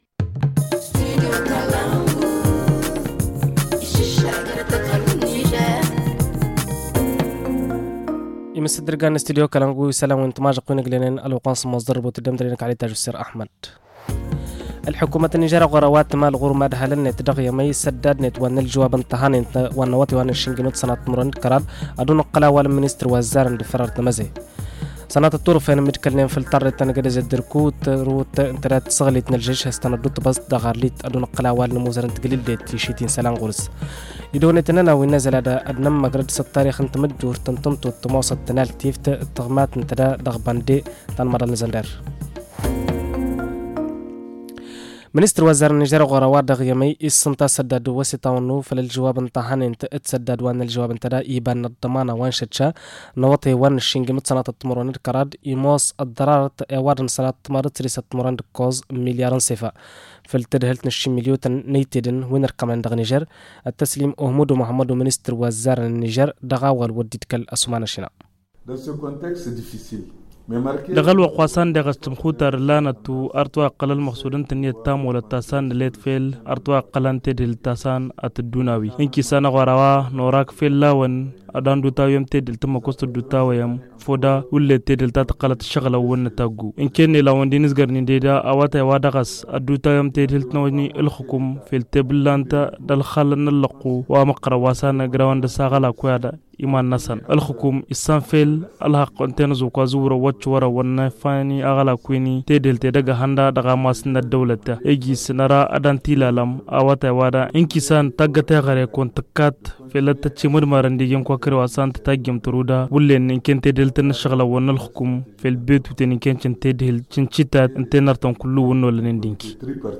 Le journal du 10 mars 2023 - Studio Kalangou - Au rythme du Niger